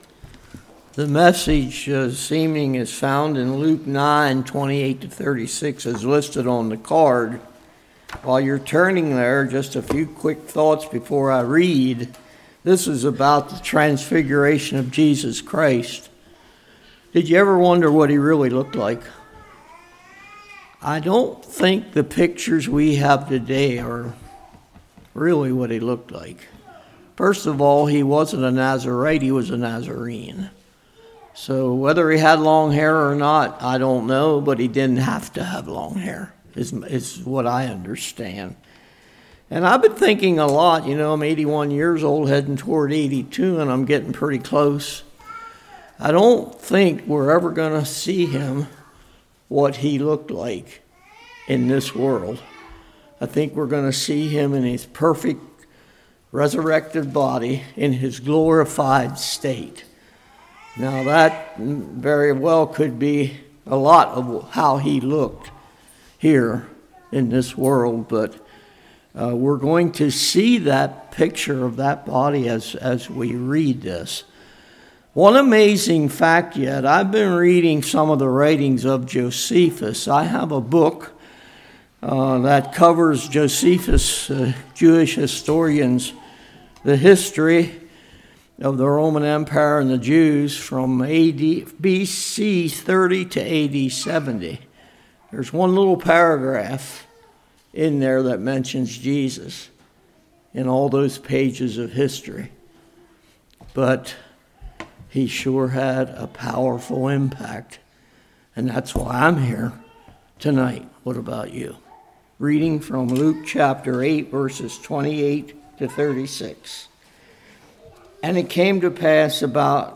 Luke 9:28-36 Service Type: Revival Jesus is both human and divine